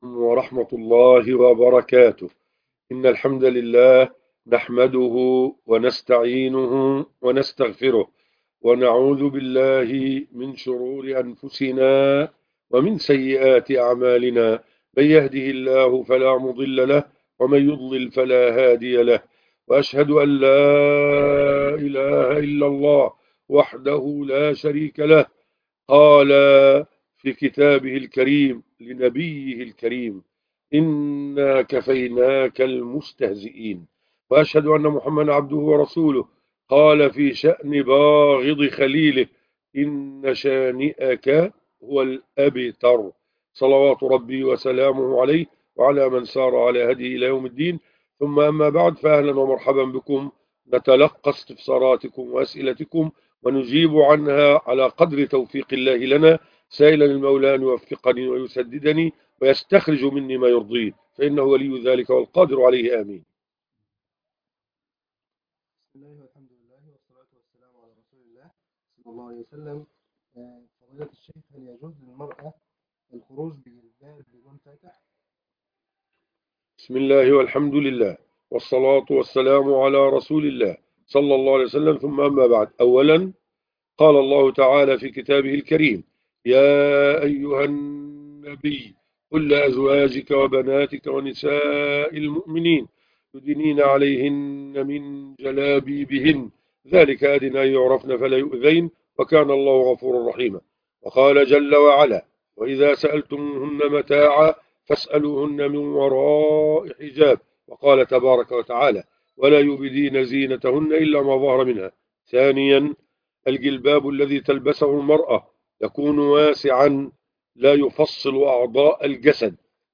فتاوى المسلم - بث مباشر للقاء الفتاوى